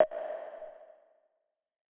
[Snr] Virus.wav